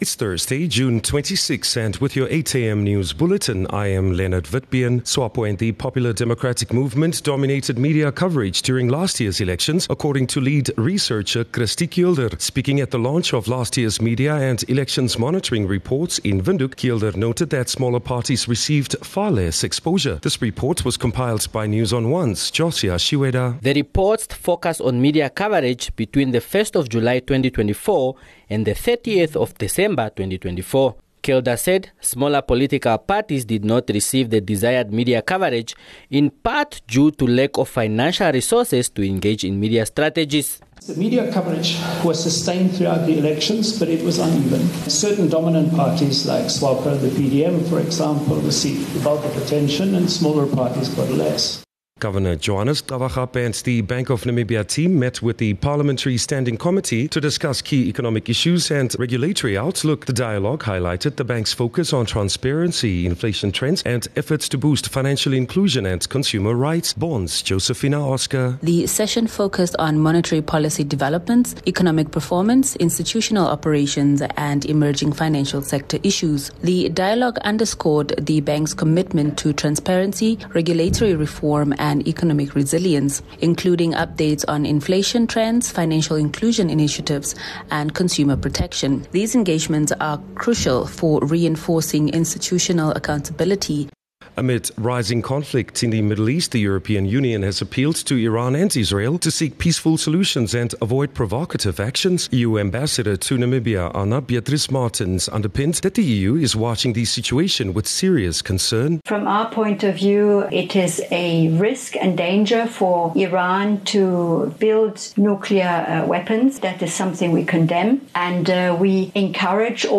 26 June-8am news